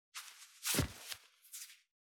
403,パーカーの音,衣服の音,衣類の音,サラッ,シャッ,スルッ,カシャッ,シュルシュル,パサッ,バサッ,
効果音洋服関係